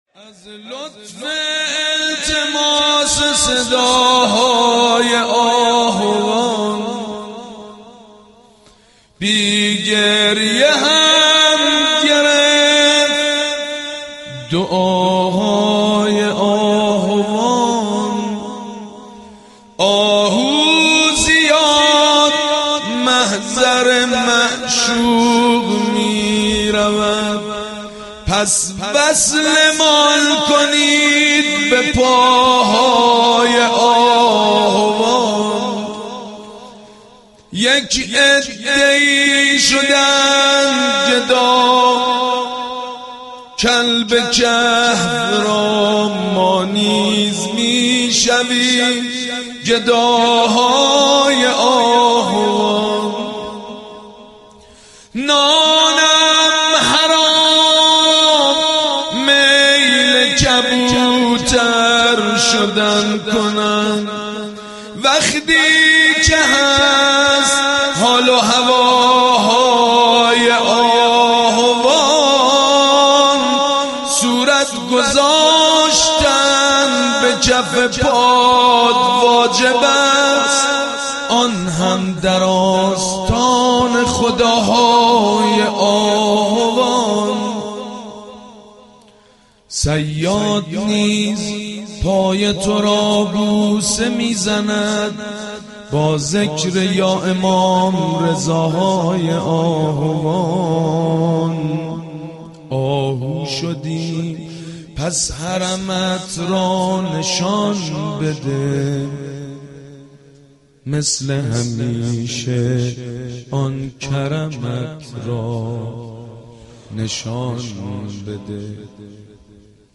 «میلاد امام رضا 1392» مدیحه سرایی: از لطف التماس صداهای آهوان